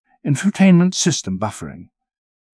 infotainment-system-buffering.wav